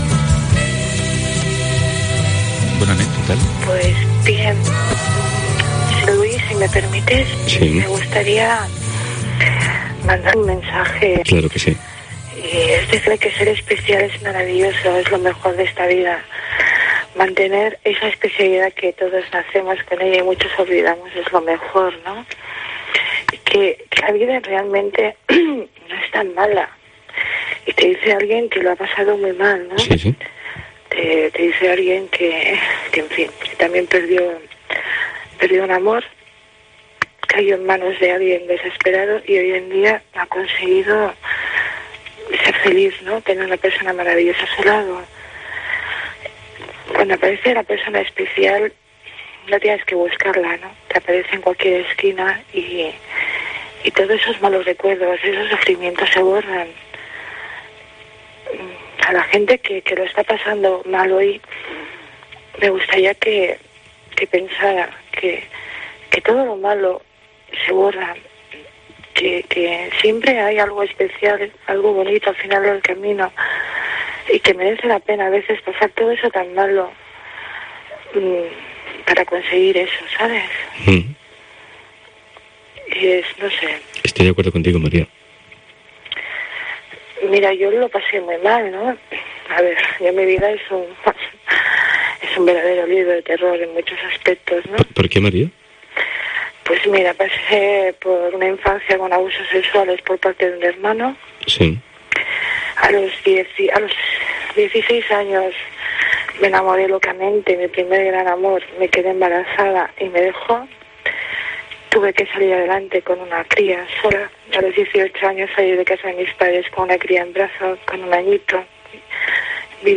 'MOMENTOS' Radio de madrugada
Todos los días de 1.30 a 6 de la madrugada a través de Cope Catalunya y Andorra.